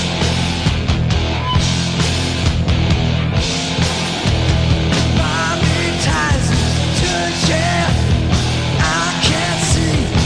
Nice show, energy is good. Professionally recorded.
This is an audience recording.